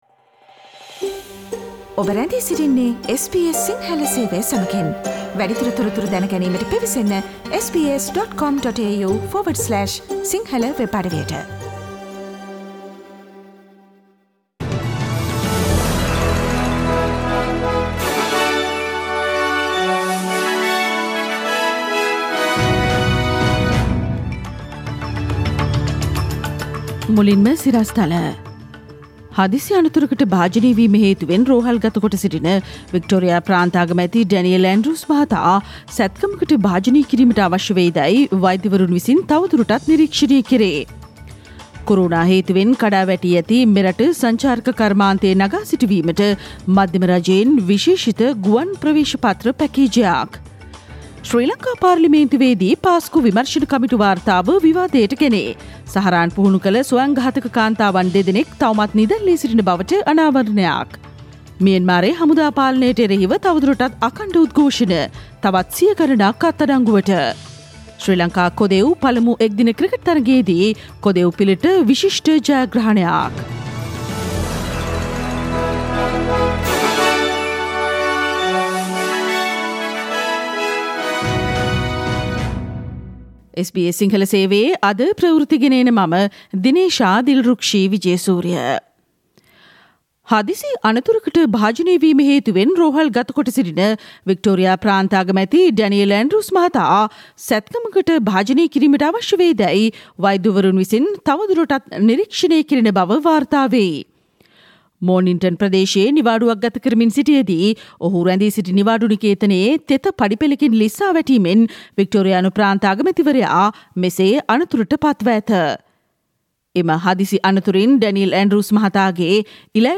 Here are the most prominent news highlights from SBS Sinhala radio daily news bulletin on Thursday 11 March 2021.